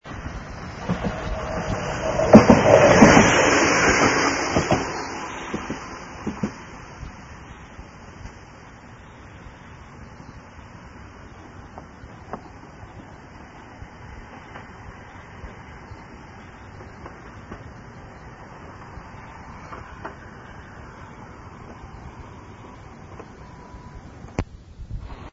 元テープは古く、また録音技術も悪いため、音質は悪いかも知れません。
E　クモニ２　（１４８ＫＢ　２５秒）　　７７年　鷺ノ宮西方にて通過音　夕方やってくるニモ電　鋳鉄制輪子の音が漂う